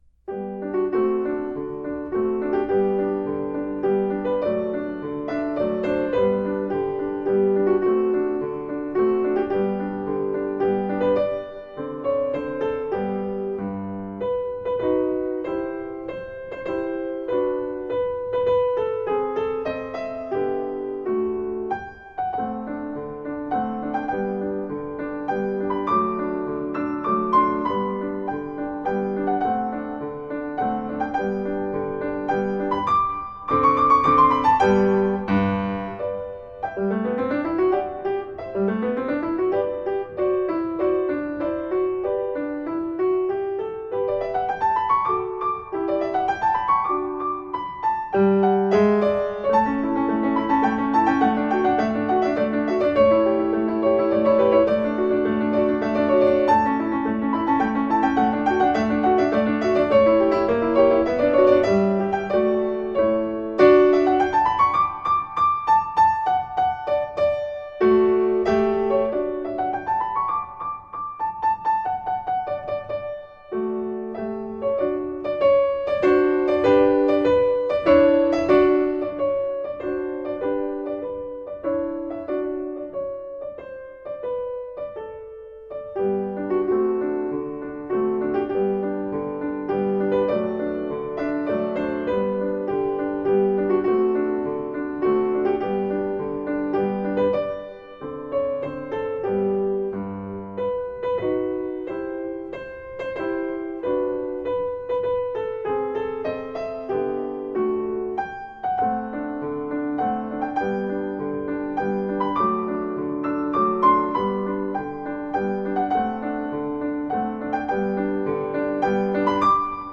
Instrumental Classical, Classical Piano